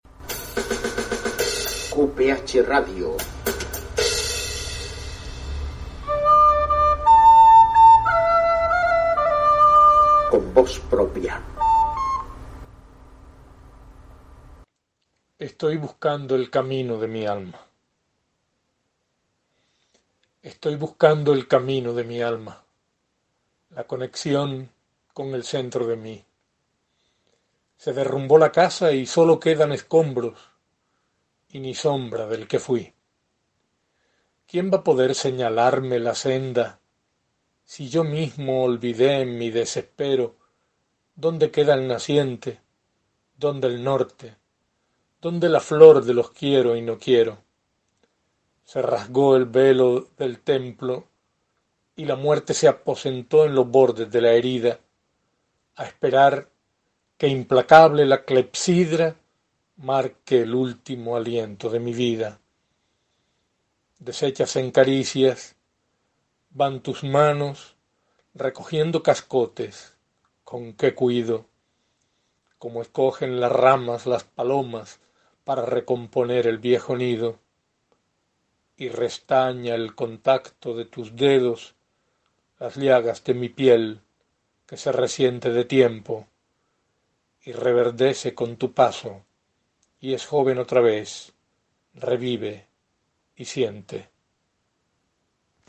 Poema rectado por su autor